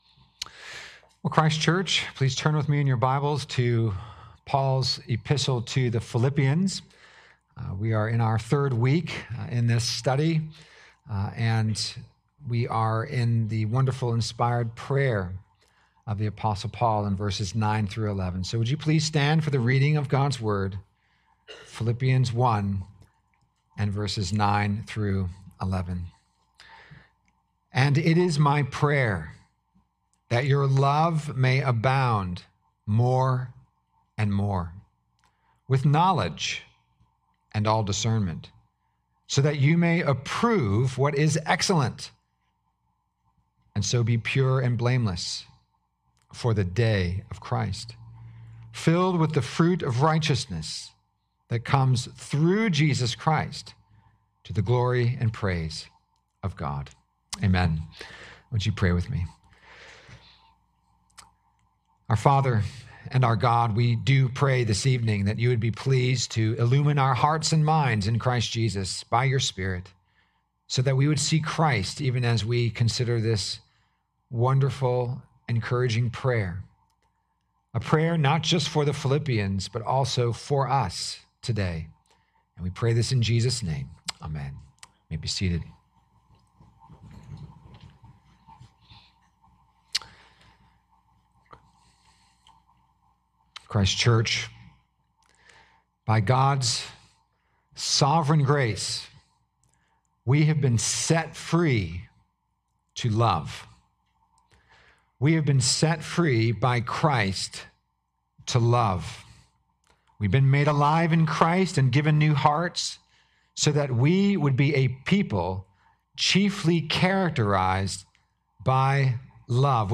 Weekly sermons of Christ Church Presbyterian Charleston, SC
Sermons